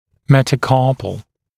[ˌmetə»kɑːpl][ˌмэтэ’ка:пл]пястный, пястная кость